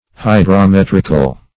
Hydrometric \Hy`dro*met"ric\, Hydrometrical \Hy`dro*met"ric*al\,